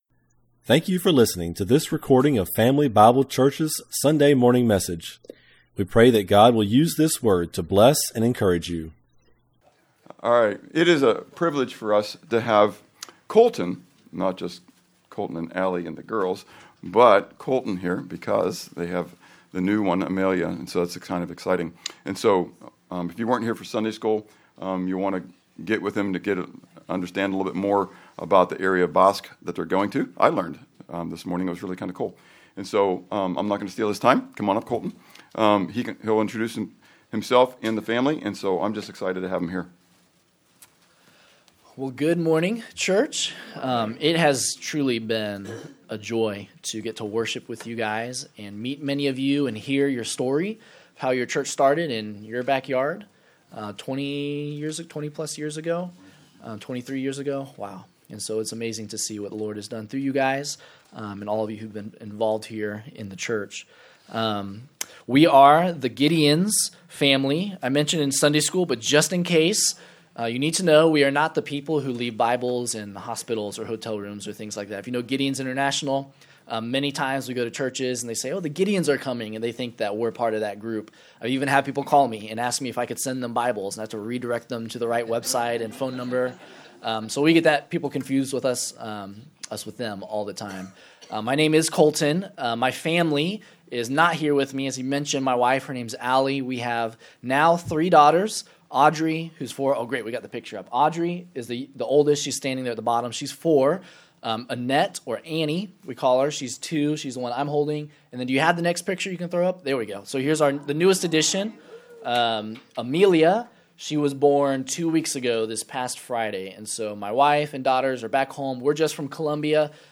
Sermon Note Sheet